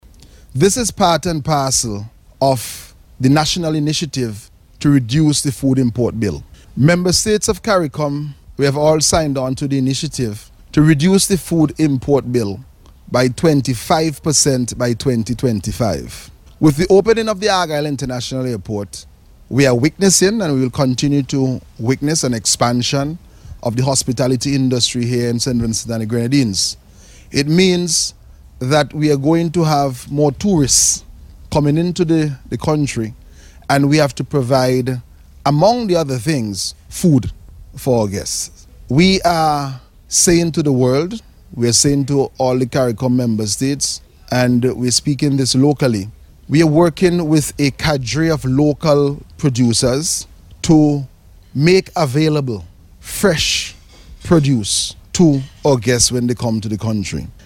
Addressing the launch, Minister of Agriculture, Saboto Caesar explained how the initiative was conceived.